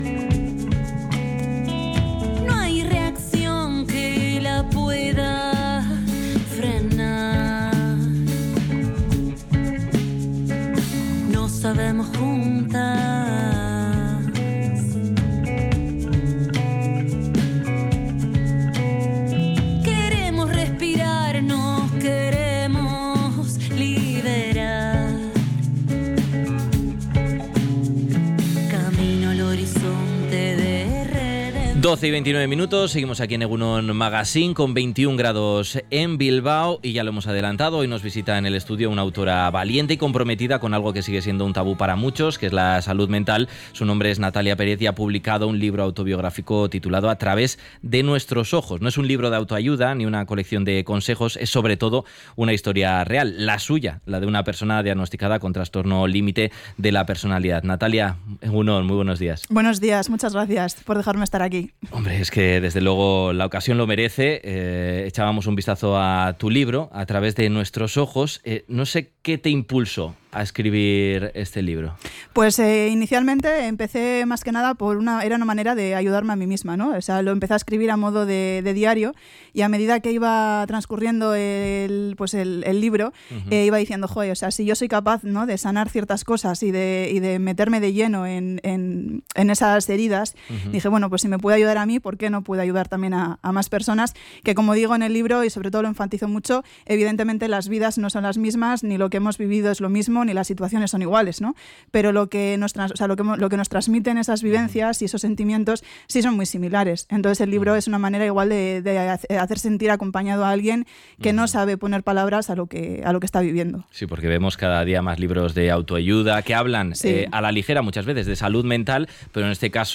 Durante la entrevista, ha recordado que el diagnóstico fue inicialmente un alivio.